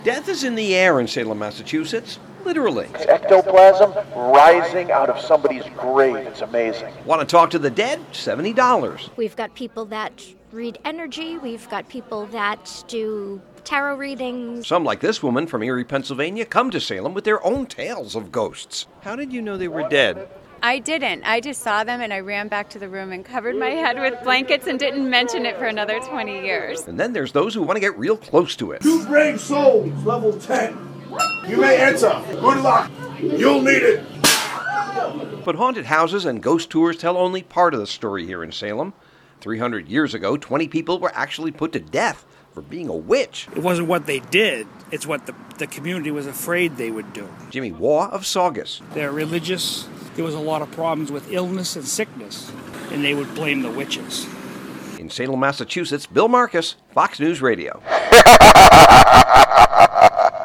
On assignment, Salem, MA